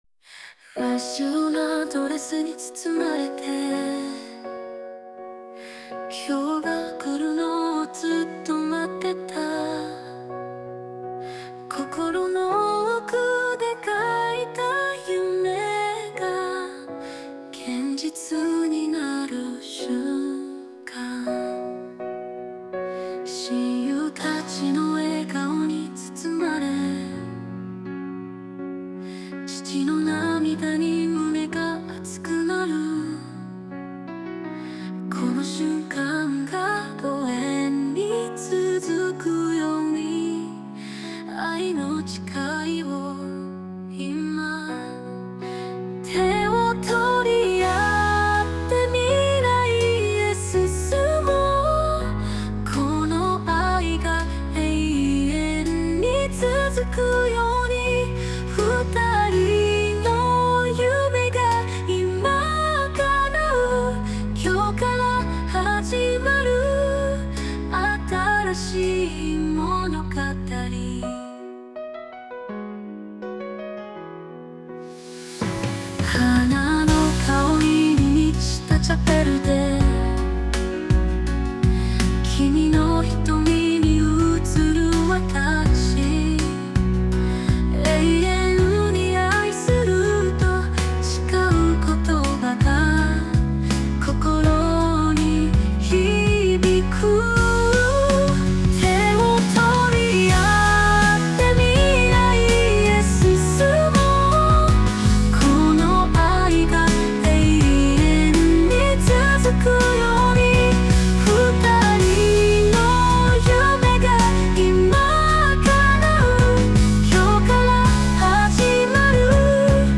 日本語・女性ボーカル曲です。